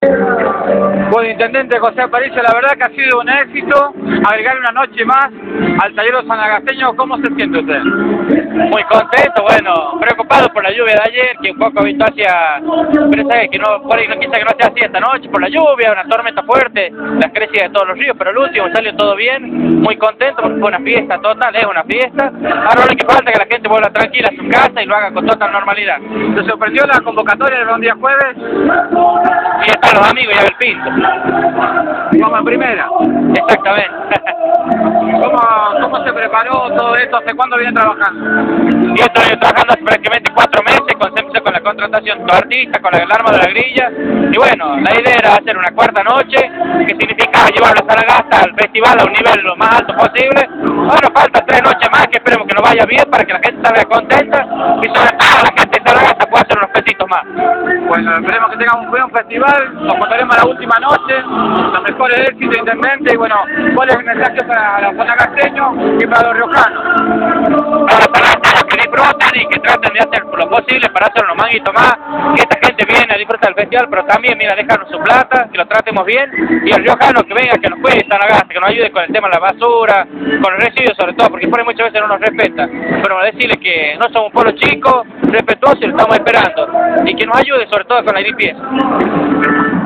José Aparicio, intendente de Sanagasta, por Radio Independiente